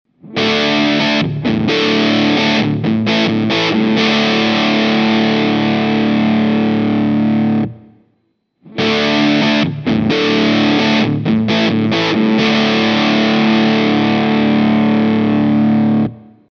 In each file I recorded the same phrase with a looper: fiirst with the Original Big Muff inserted in a loop just before the Stack and after with the Kemper Stomps. I used HiWatt profiles here, but same results I obtained with Marshall and Fender Amps…
Here some Audio Tests (in each phrase is played first the Original Muff and then the Kemper Emulation):
TEST-BigMuff-Ram-02.mp3